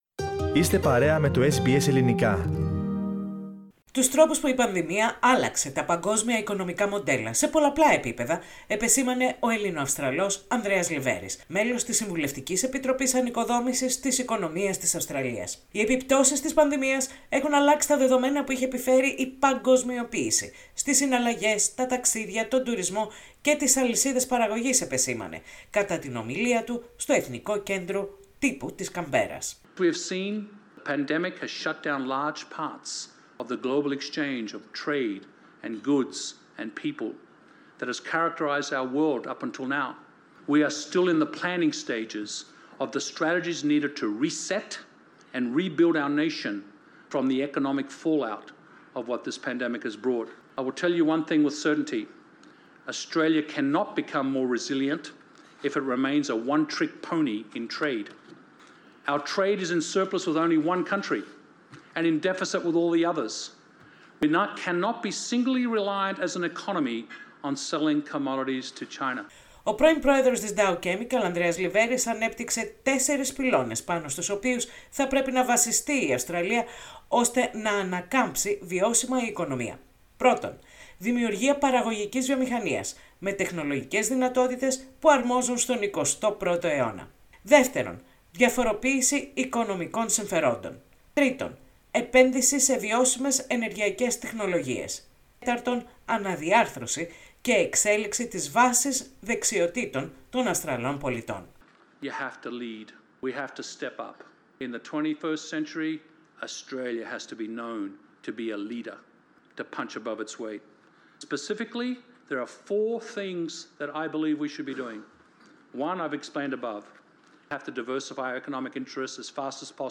Τέσσερις πυλώνες πάνω στους οποίους η Αυστραλία μπορεί να στηρίξει την οικονομία της μετά την πανδημία παρουσίασε ο ομογενής Ανδρέας Λιβέρης, στο National Press Club, ως σύμβουλος της Αυστραλιανής κυβέρνησης. Ανάμεσα στις προτάσεις του είναι η αναβάθμιση της εγχώριας βιομηχανίας και η στροφή προς τo φυσικό αέριο και τις ανανεώσιμες πηγές ενέργειας.